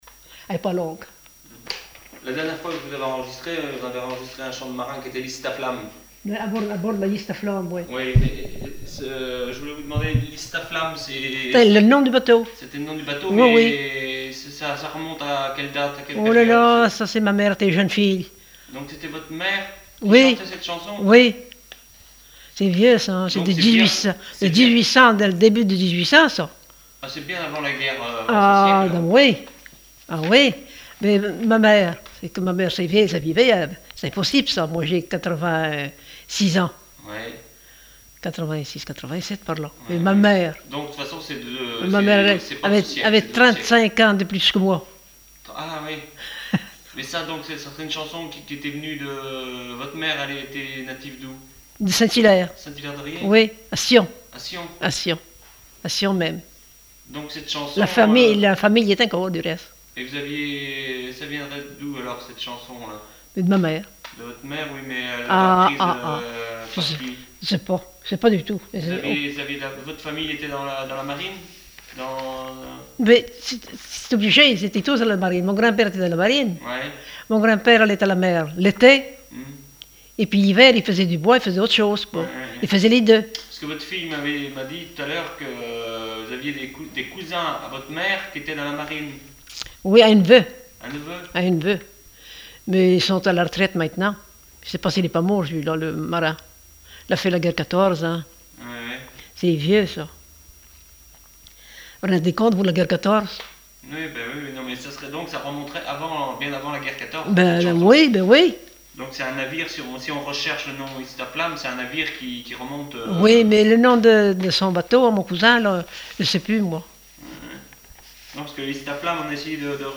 Enquête sur les chansons populaires
Témoignage